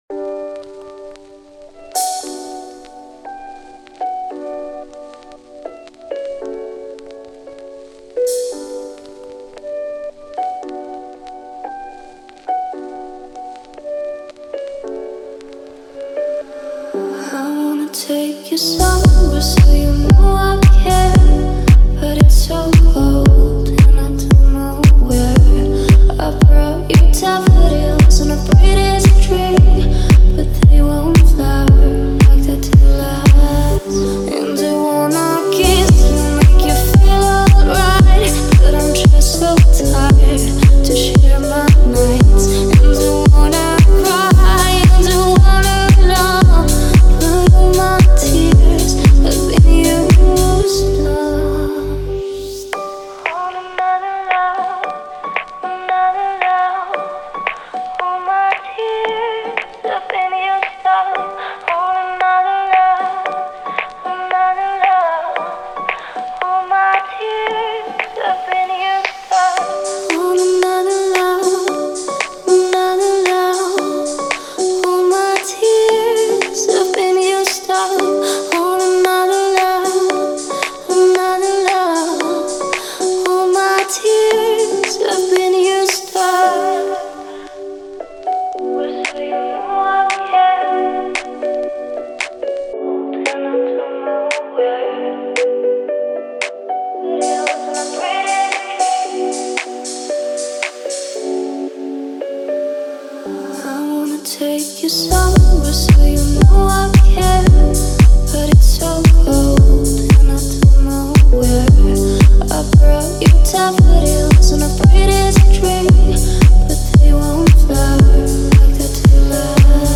которая сочетает в себе элементы поп и электронной музыки.